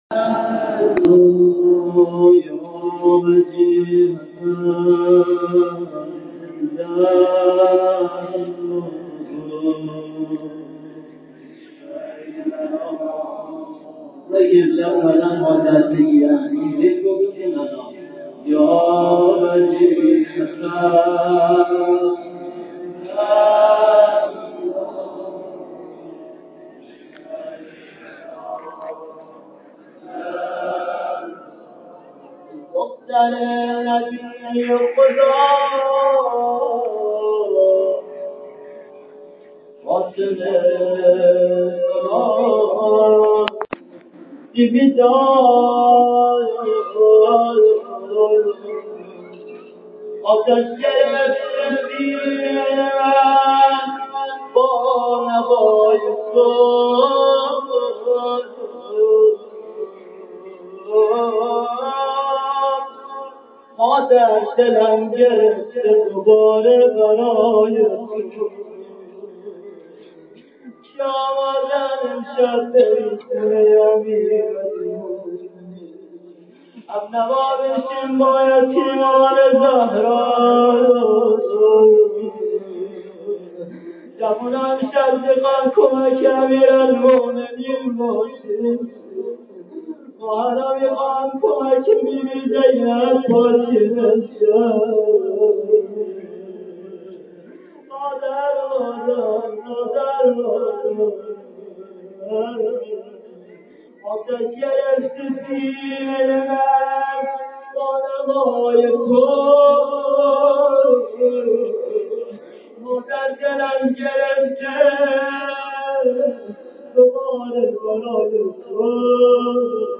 روضه شب شهادت حضرت فاطمه زهرا س